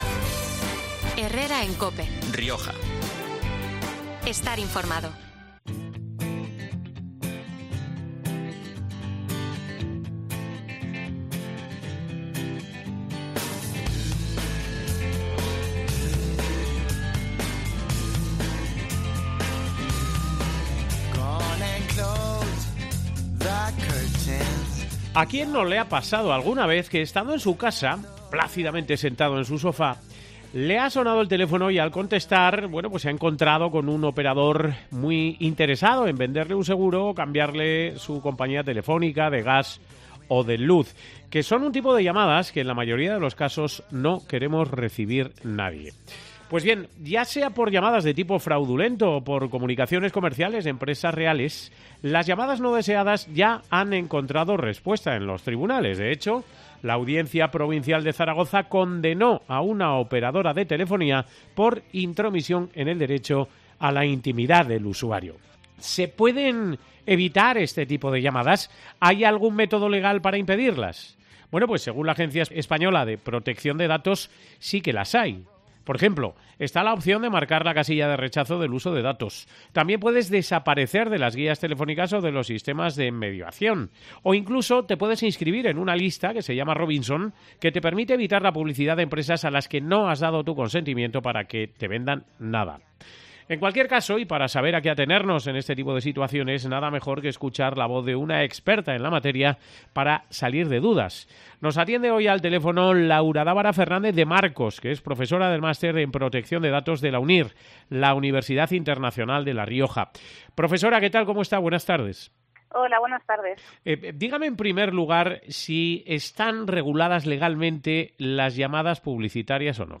En cualquier caso, y para saber a qué atenernos en este tipo de situaciones, nada mejor que escuchar la voz de una experta en la materia para salir de dudas.